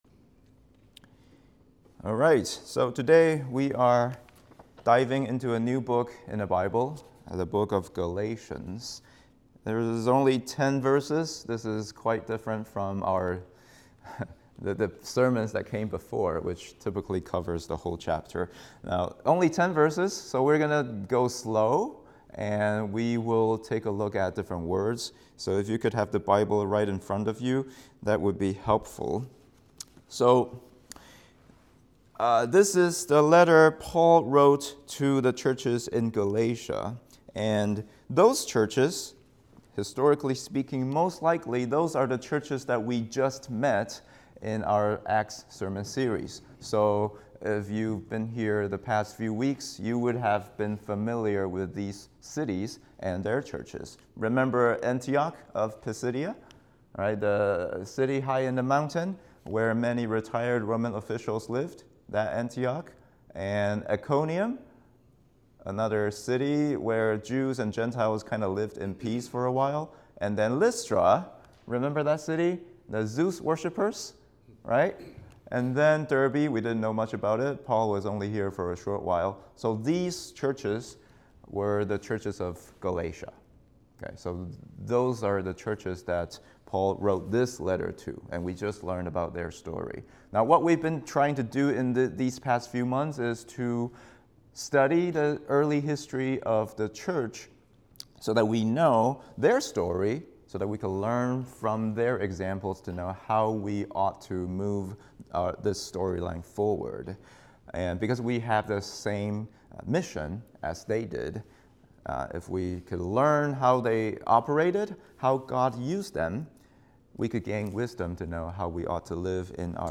English Sermon